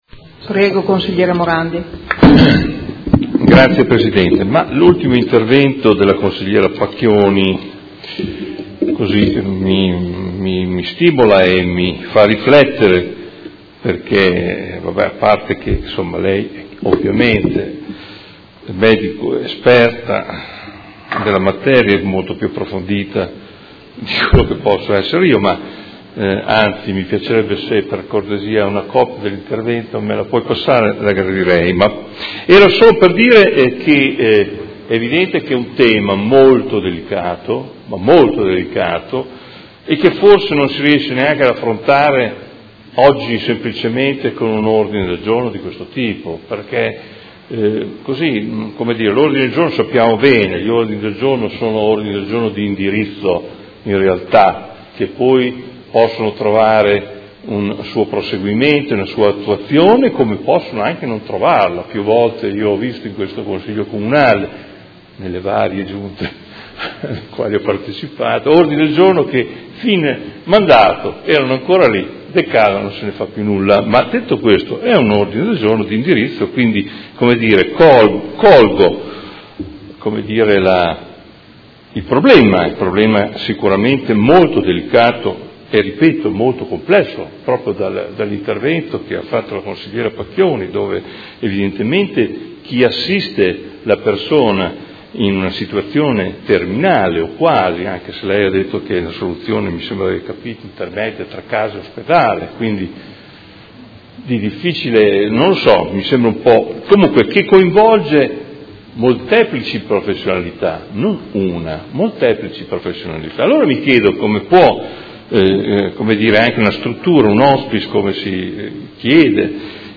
Seduta del 04/06/2018. Dibattito su Ordine del Giorno presentato dai Consiglieri Arletti, Fasano, Baracchi, Bortolamasi, De Lillo, Pacchioni, Liotti, Di Padova, Venturelli, Morini e Lenzini (PD) avente per oggetto: Anche Modena si merita un hospice “territoriale” - la programmazione sanitaria preveda una struttura per il territorio modenese analogamente alle altre province della Regione Emilia Romagna ed emendamenti